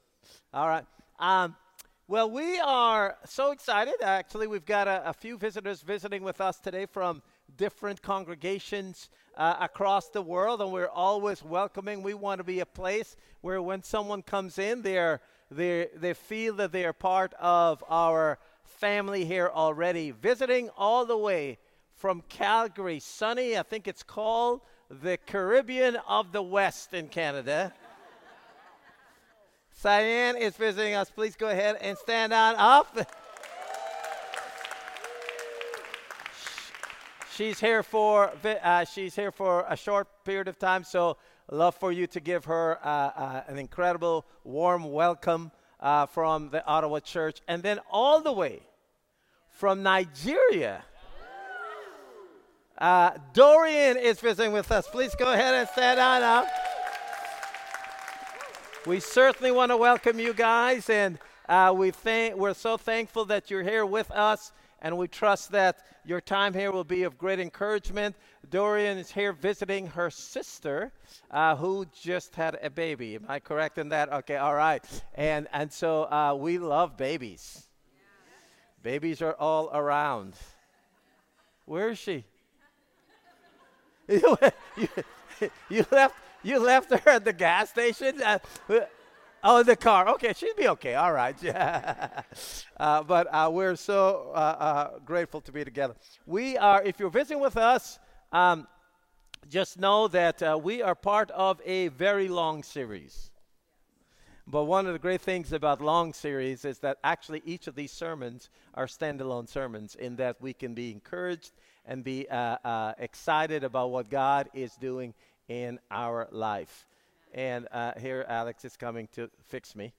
Ottawa Church Of Christ Sermon Podcasts